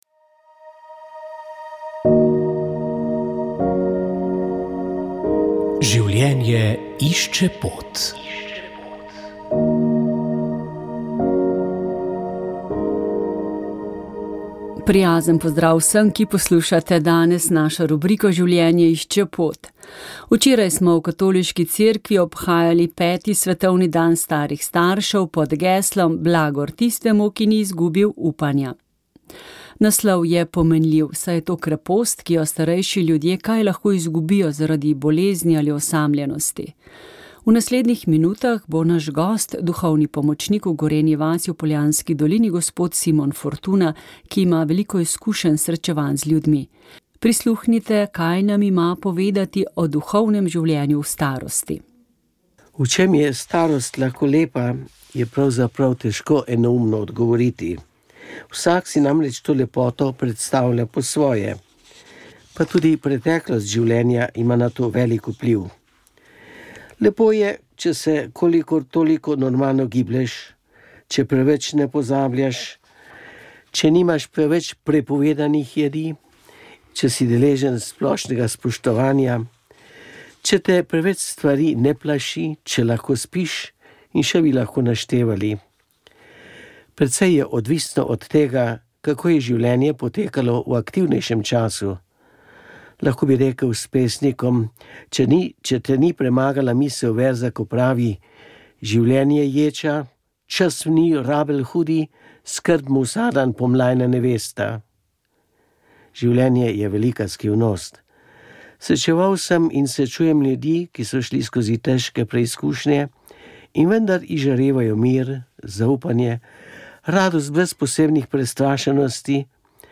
Njemu v spomin smo pripravili oddajo Naš gost in v njej nanizali utrinke njegovega iskrivega pripovedovanja.